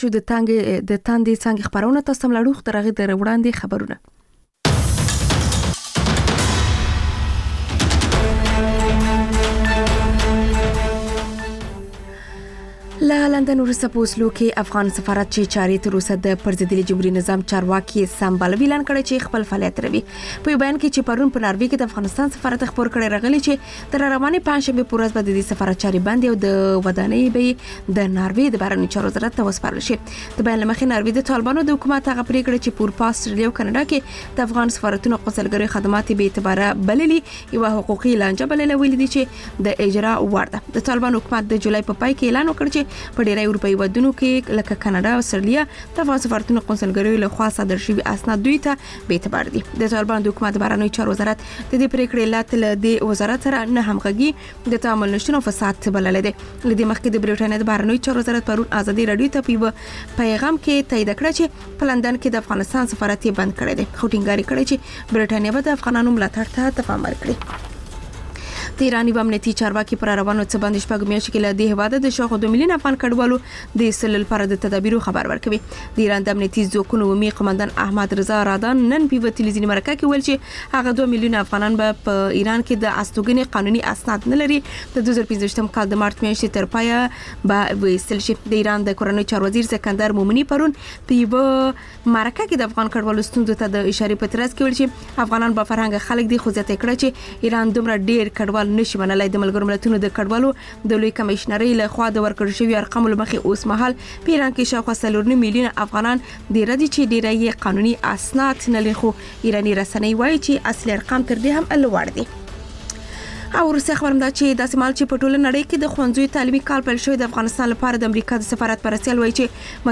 لنډ خبرونه - تاندې څانګې (تکرار)